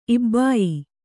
♪ ibbāyi